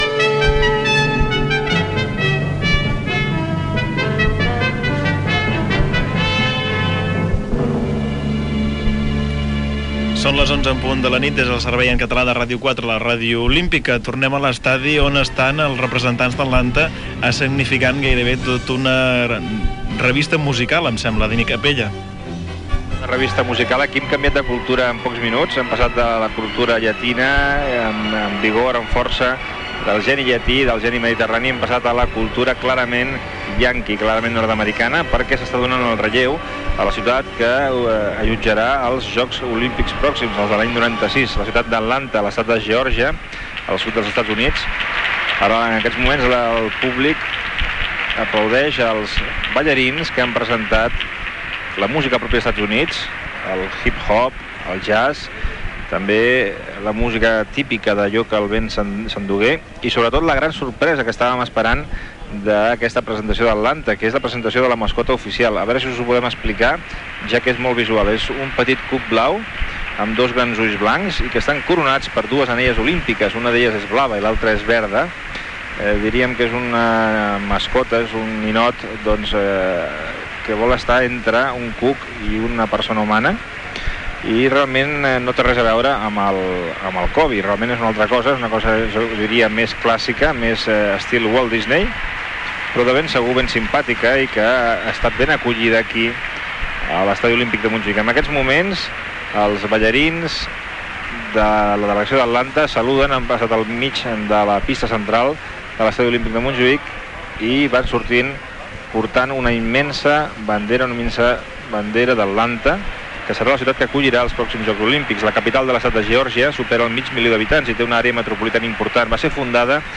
Sintonia, hora, identificació connexió amb l'estadi Lluís Companys on s'està fent la cerimònia de cloenda dels Jocs Olímpics de Barcelona 1992, indicatiu, informatiu en anglès amb la connexió amb l'estadi Lluís Companys, indicatiu, hora, segueix la transmissió de la cerimònia de coloenda en castellà, indicatiu, segueix la transmissió en francès amb l'actució de Los Manolos, Los Amaya i Peret tocant rumba catalana Gènere radiofònic Informatiu